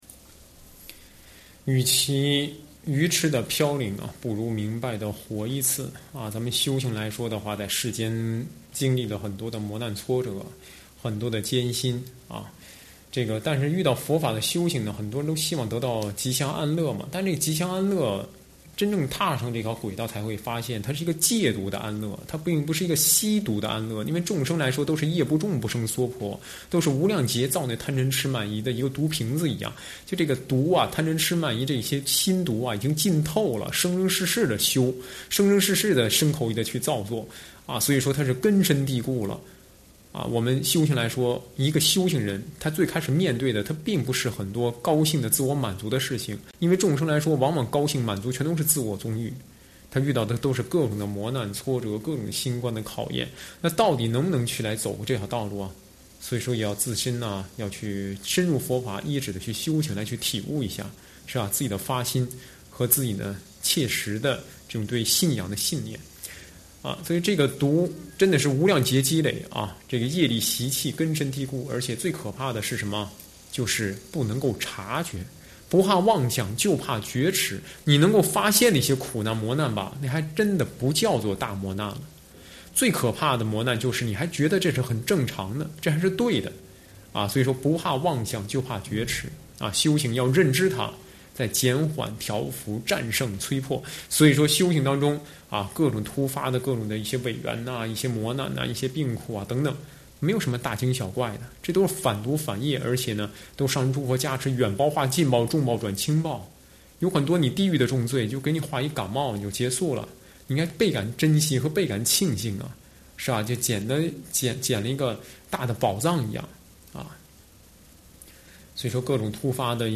随笔开示
上师开示讲法